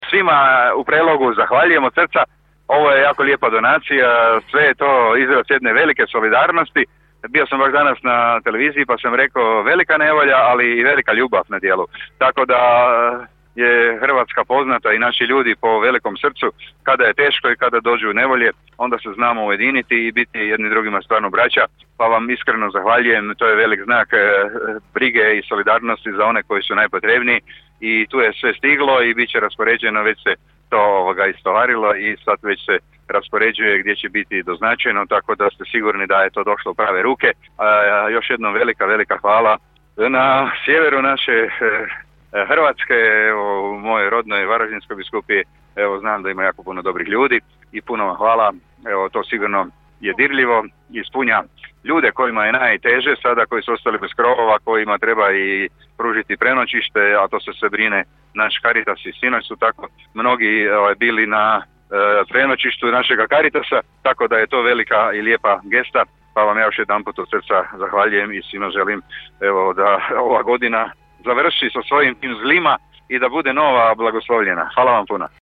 Peloška delegacija je istog dana u večernjim satima donaciju dostavila u Caritas Sisačke biskupije, gdje ih je dočekao biskup Vlado Košić, a on je zahvalu uputio u javljanju uživo u program našeg radija: